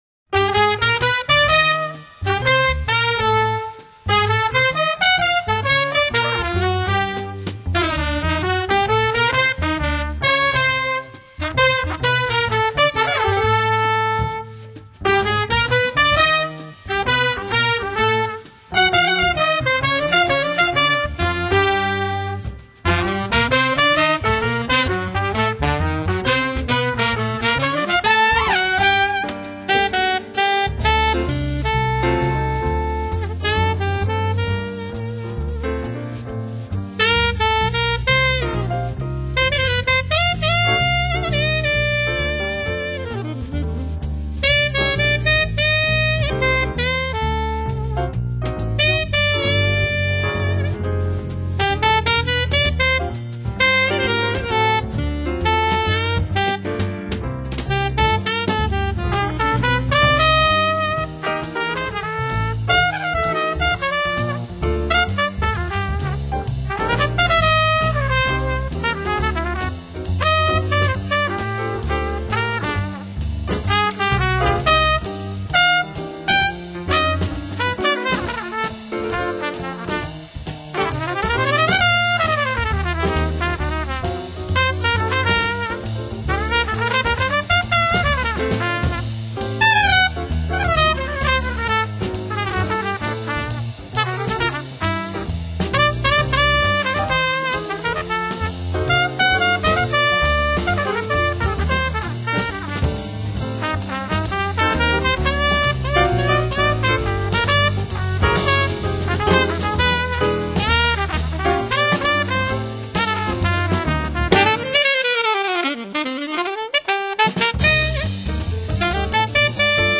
幽怨柔美的旋律，其他爵士艺人得以即兴发挥的弹性空间甚大。